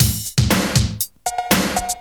• 80 Bpm Drum Loop Sample A# Key.wav
Free drum loop - kick tuned to the A# note. Loudest frequency: 3968Hz
80-bpm-drum-loop-sample-a-sharp-key-6mj.wav